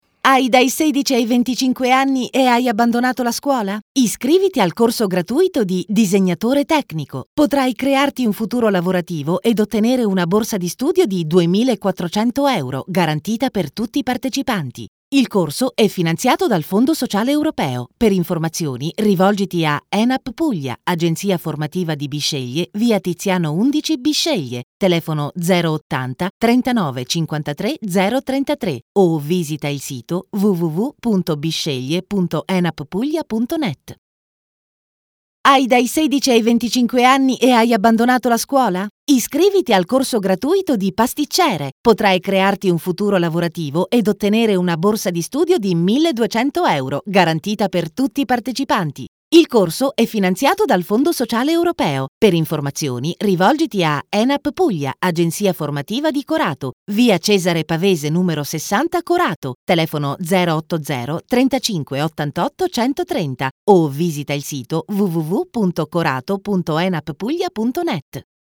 Sprechprobe: Werbung (Muttersprache):